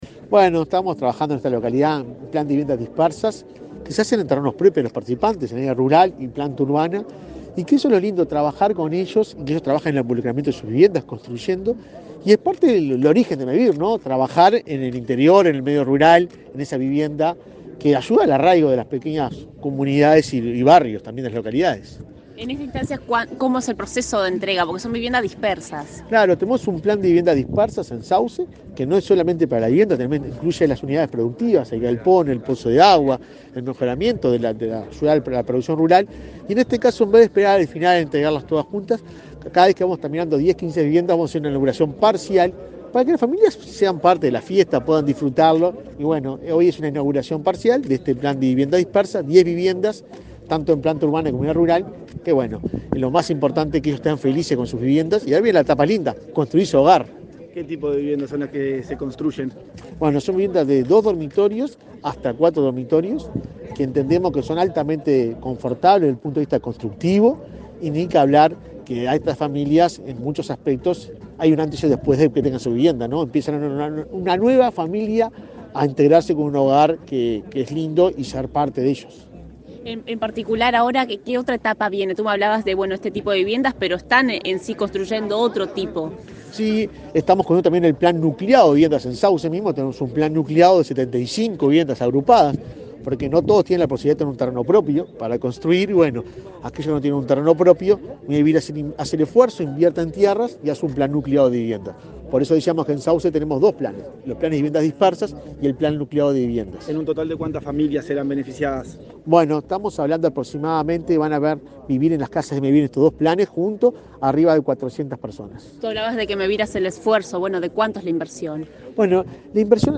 Declaraciones del presidente de Mevir a la prensa
Luego, Delgado dialogó con la prensa.